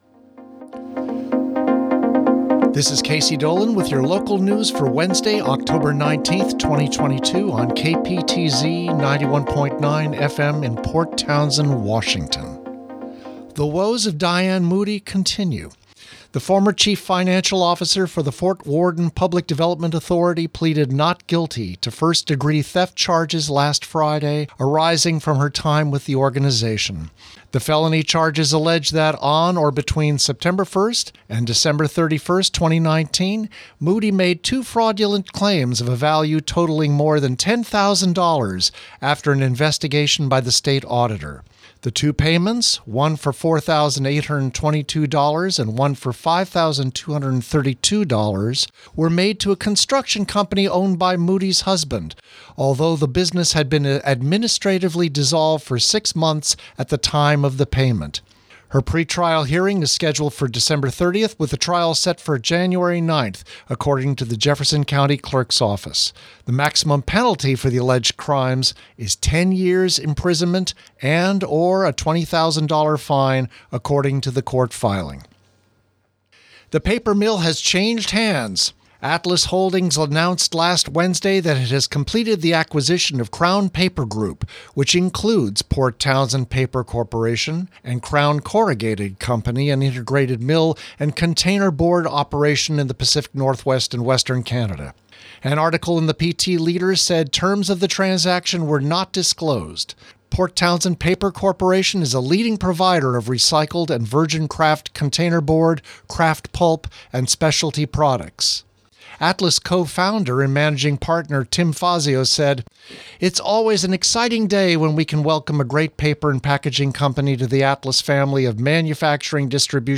221019 Local News Wednesday